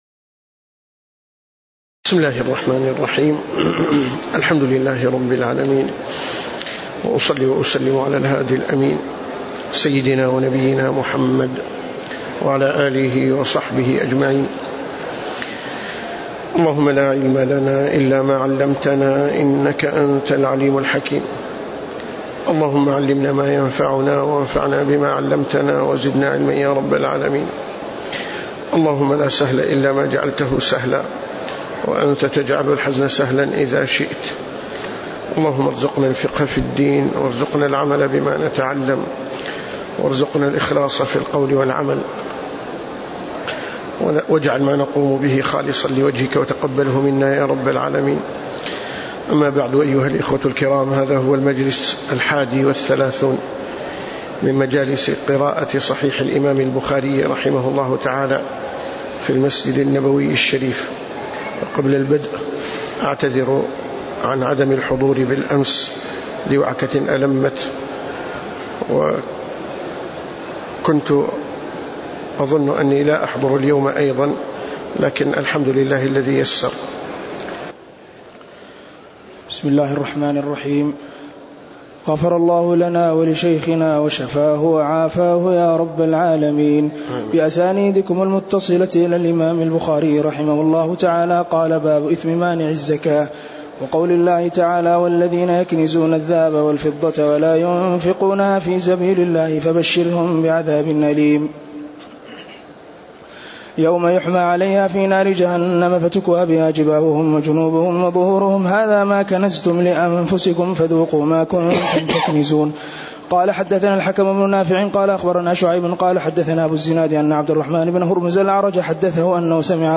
تاريخ النشر ٢٢ صفر ١٤٣٨ هـ المكان: المسجد النبوي الشيخ